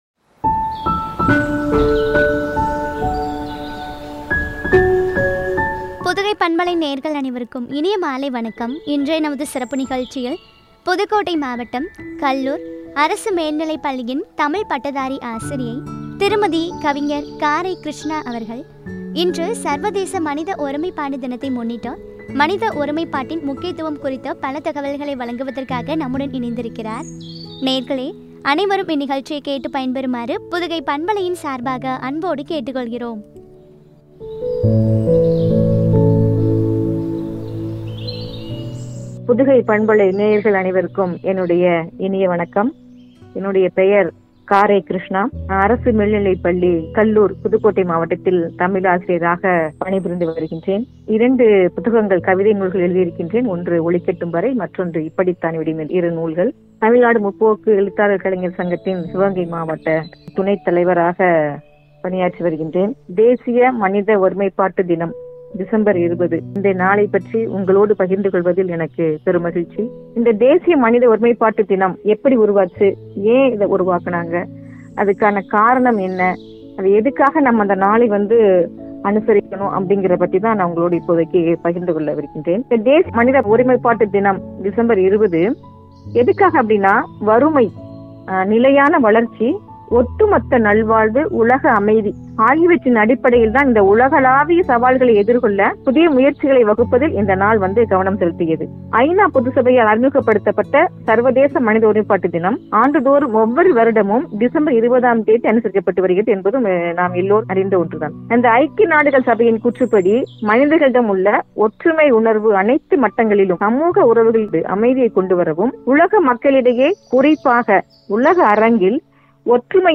மனித ஒருமைபாட்டின் முக்கியத்துவம் பற்றிய உரையாடல்.